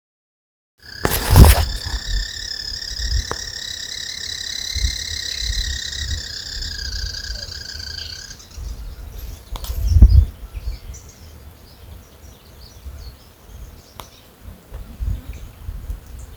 Olivaceous Woodcreeper (Sittasomus griseicapillus)
Location or protected area: Reserva Privada San Sebastián de la Selva
Condition: Wild
Certainty: Photographed, Recorded vocal